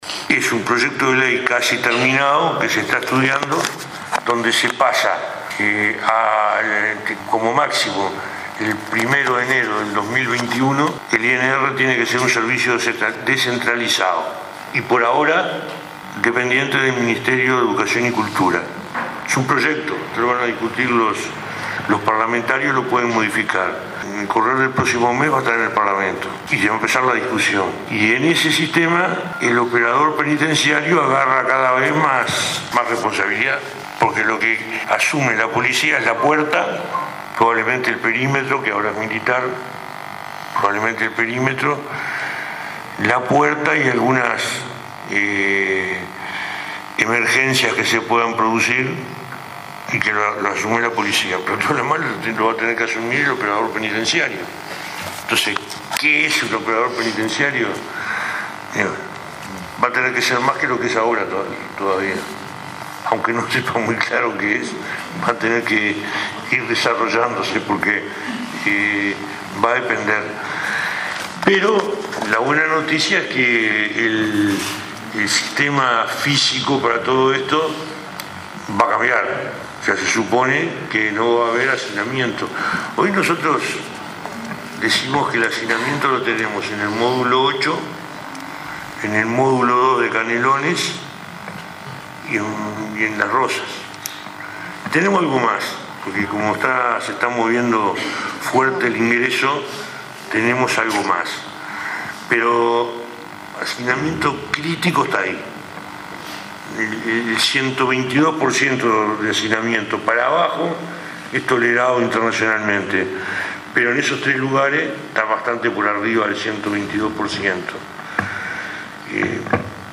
“El operador penitenciario es el eje de la rehabilitación, pues al no ser un policía que está junto a los internos no lo visualizan como un enemigo”, dijo el ministro del Interior, Eduardo Bonomi, en el acto de ingreso de 84 operadores al Instituto Nacional de Rehabilitación.